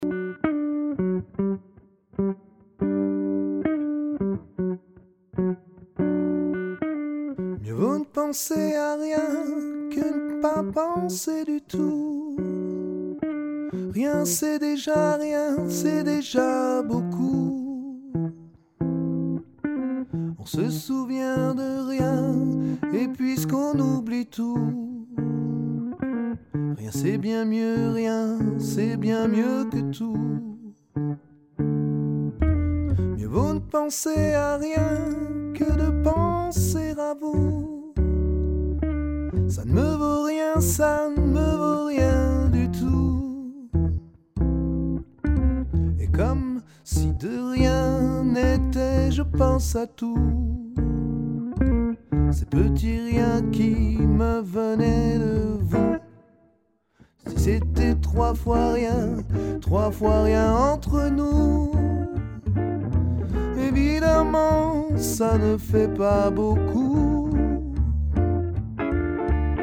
Chanteur
45 - 59 ans - Baryton